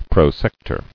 [pro·sec·tor]